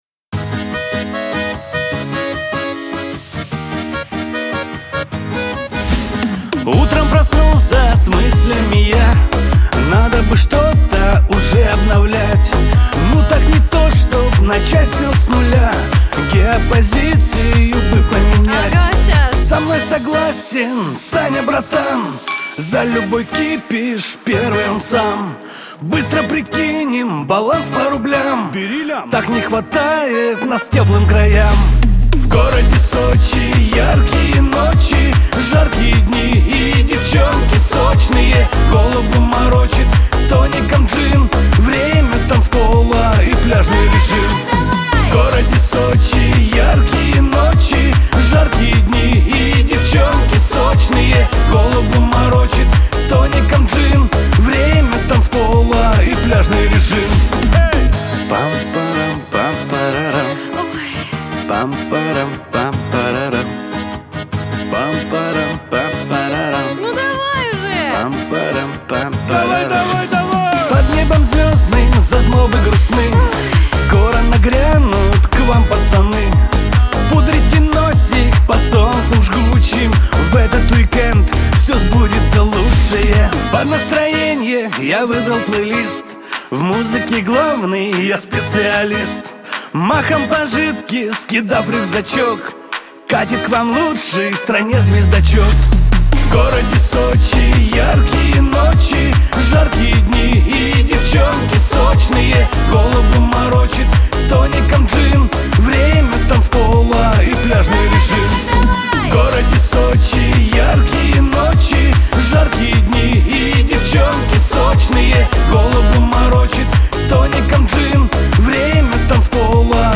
pop
Веселая музыка